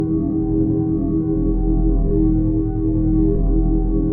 sci-fi_forcefield_hum_loop_04.wav